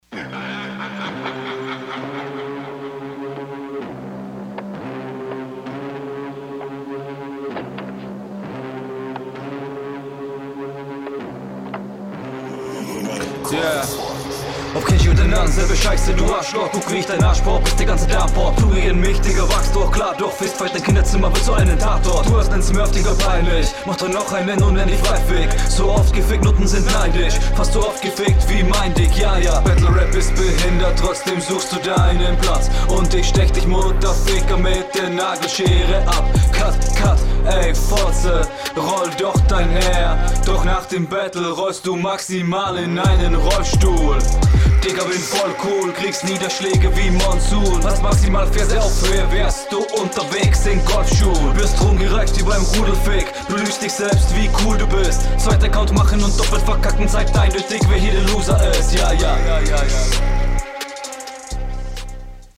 Ok, das Instrumental bietet hier schon mal mehr Battlerap-Atmo, flowlich kommst du hier auch echt …
Flow: gut geflowt, hier und da etwas vernuschelt aber im Großen und Ganzen on point.